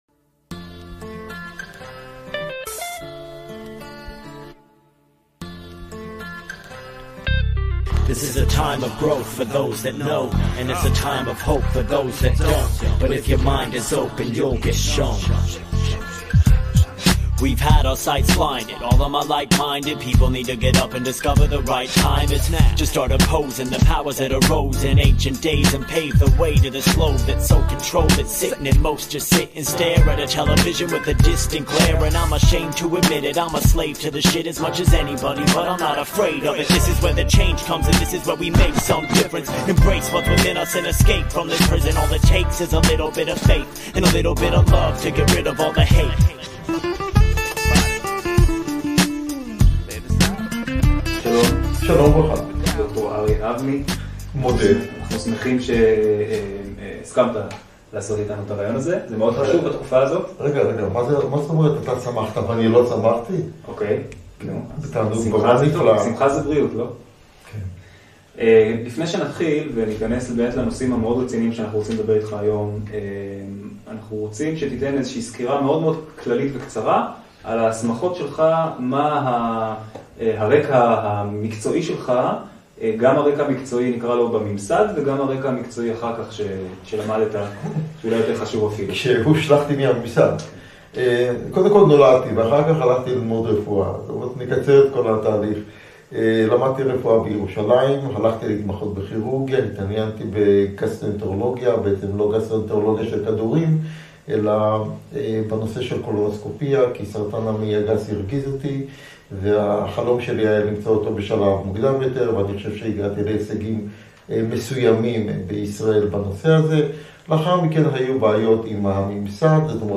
ראיון מלא